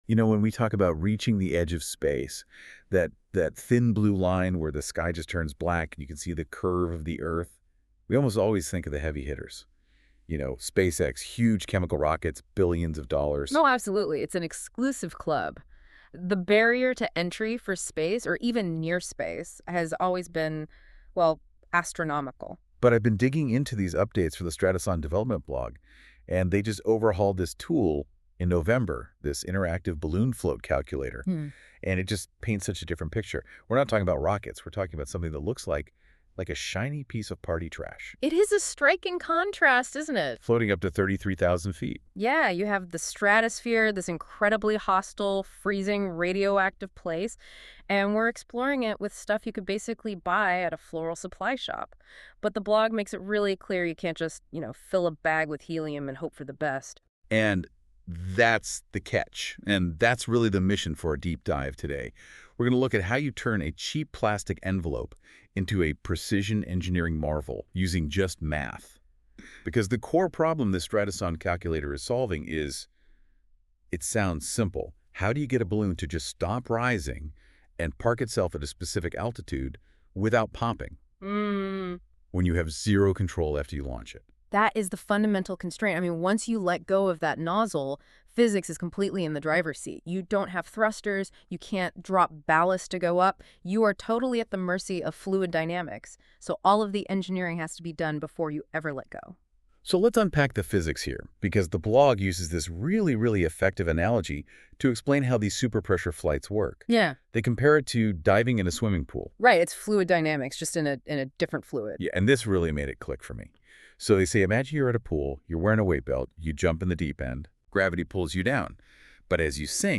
🎧 Listen to this article: NotebookLM Podcast An AI-generated audio discussion created by Google’s NotebookLM